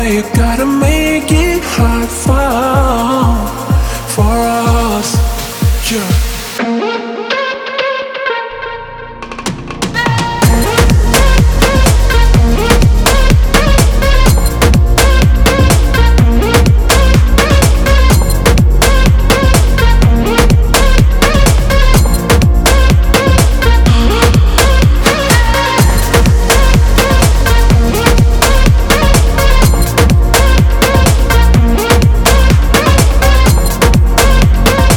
Жанр: Африканская музыка
# Afro House